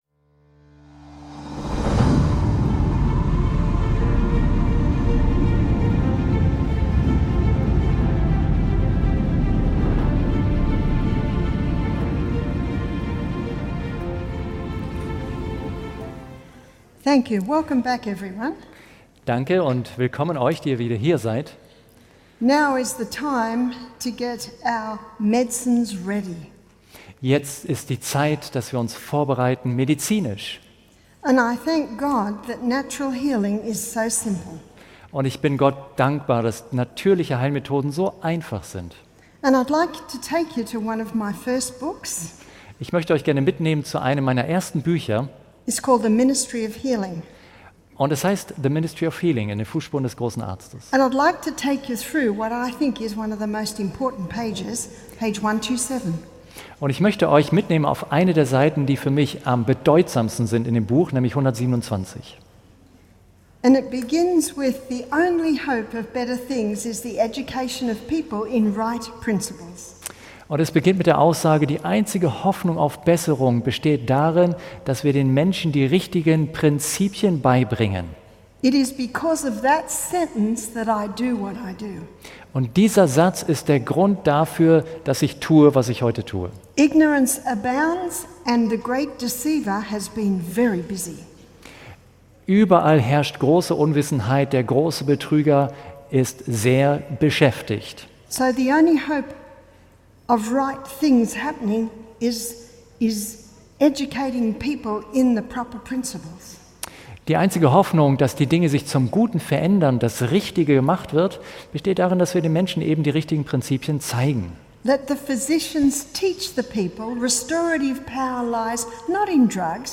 Eine faszinierende Reise in die Welt der natürlichen Heilmethoden, die den Fokus auf den eigenen Körper und dessen Selbstheilungskräfte legt. Der Vortrag beleuchtet grundlegende Prinzipien, die Gesundheit fördern, und gibt Einblicke in wirksame Hausmittel.